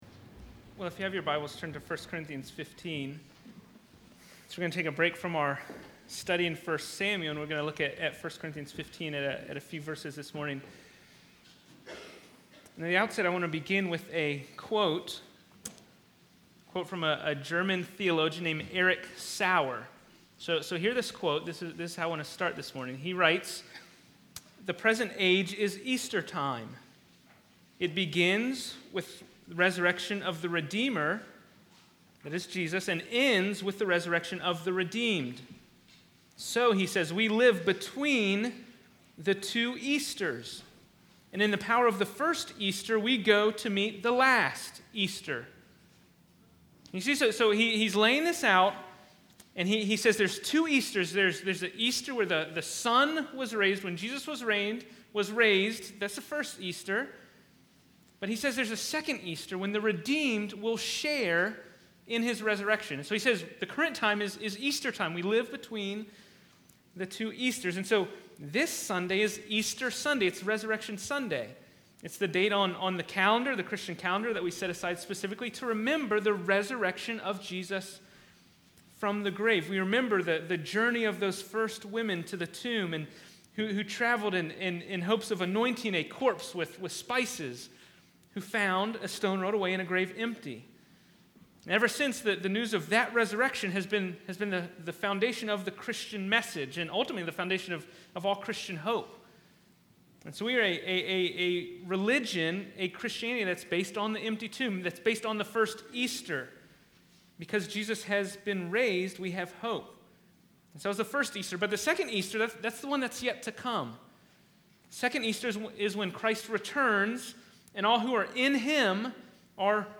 Fox Hill Road Baptist Church Sermons
Easter Sunday 4.1.18.mp3